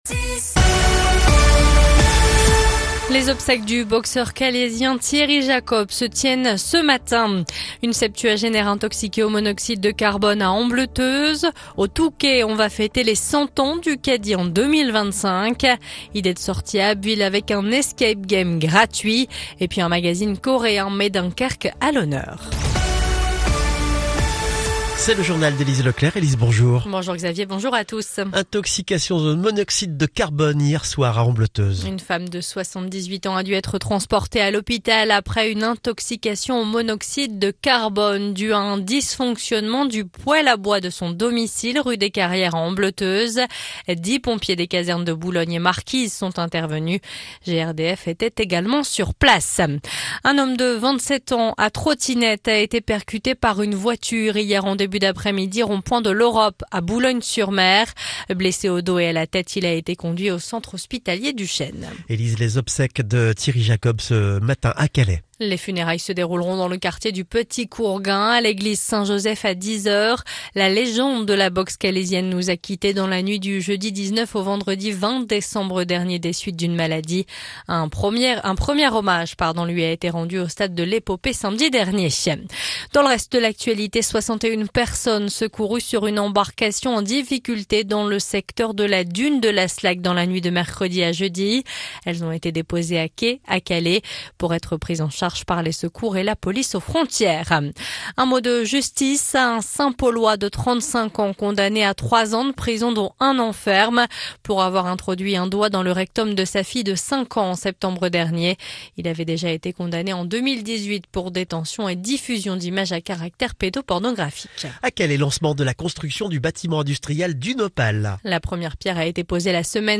Le journal du vendredi 27 décembre